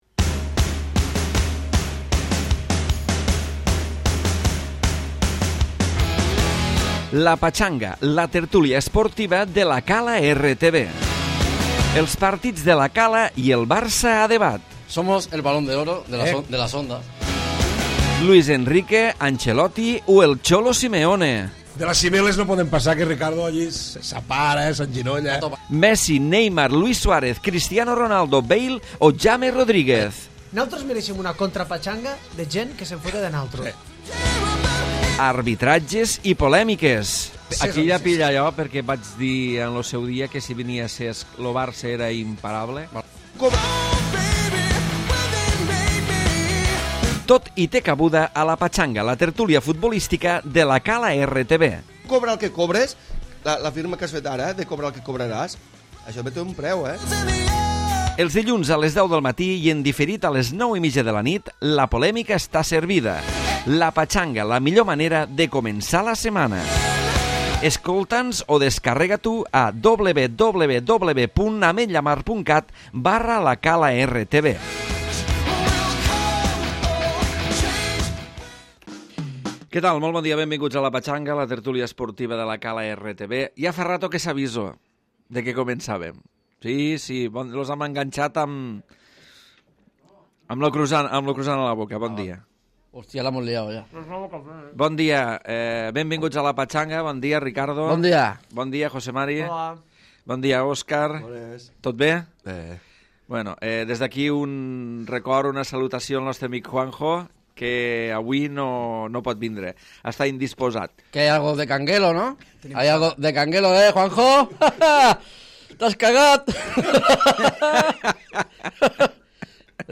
Tertúlia d'actualitat futbolistica prèvia al Clàssic Barça-Madrid del diumenge.